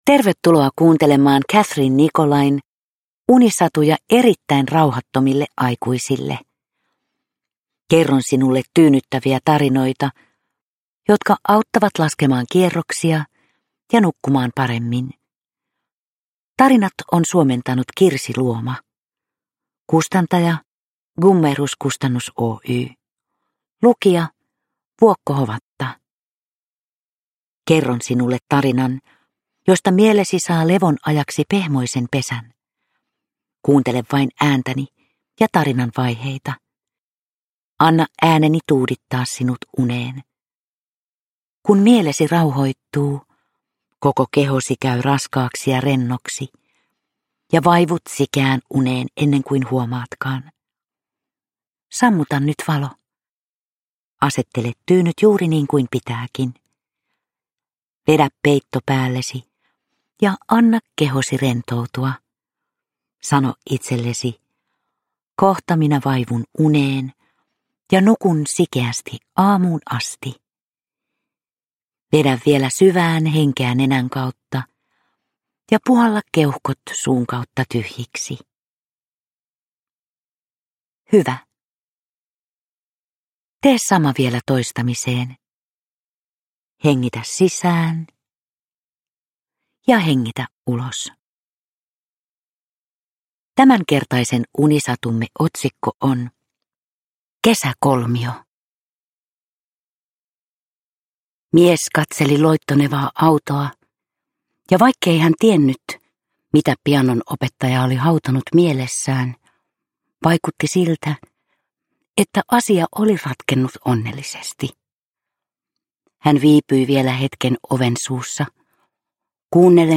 Unisatuja erittäin rauhattomille aikuisille 4 - Kesäkolmio – Ljudbok